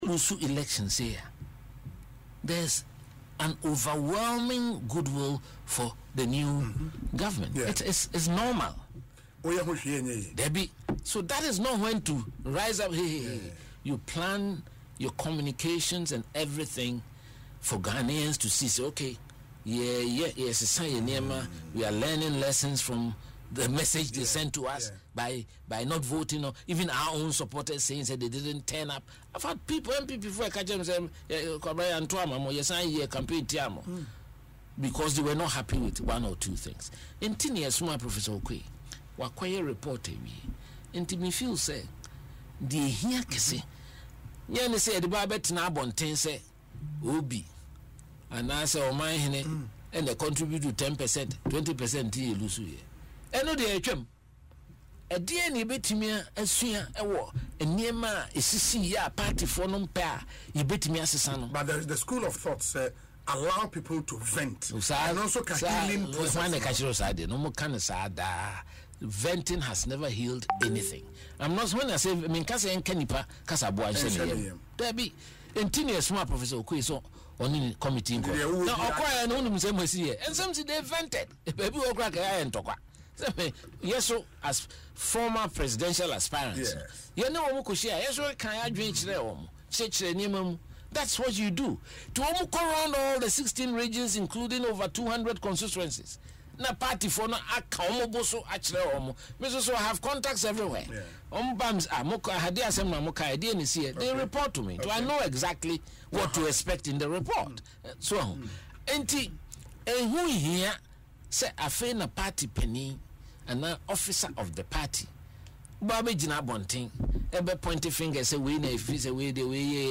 In an interview on Asempa FM Ekosii Sen, the former General Secretary emphasised that the electoral loss should be a moment of sober reflection for the NPP.